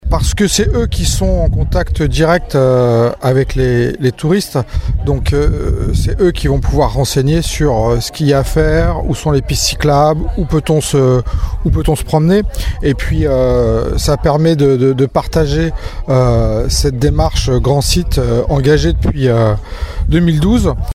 Sébastien Bourbigot, vice-président de la Communauté d’agglomération Rochefort océan en charge du tourisme, nous explique pourquoi il est important de sensibiliser les prestataires :